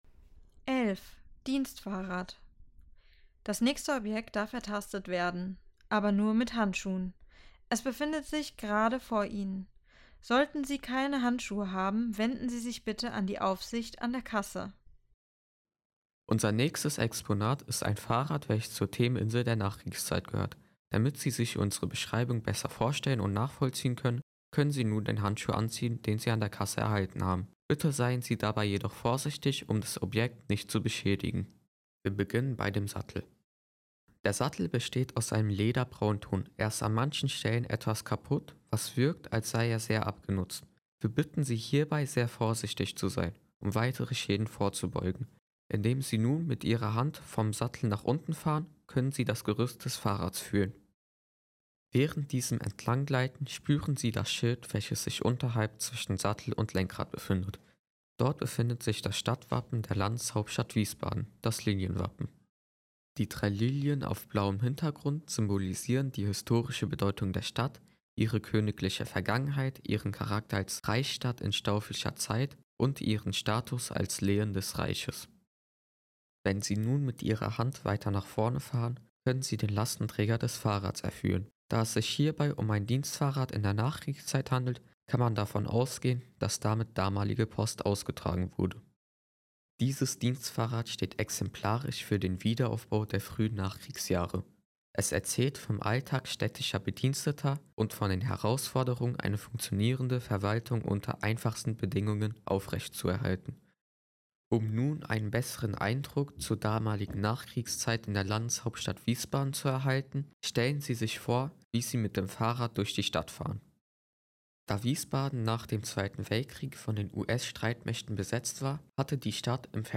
Audioguide - Dienstfahrrad
Für blinde und sehbeeinträchtigte Menschen gibt es im sam einen Audioguide, der das Bodenleitsystem ergänzt. Anhand verschiedener Objekte, die zum Teil auch ertastet werden können, führt der Guide durch die Stadtgeschichte Wiesbadens.